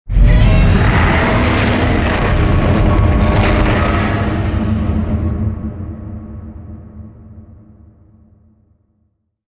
Godzilla Filius's Roar Sound Buttons
godzilla-filius-roar.mp3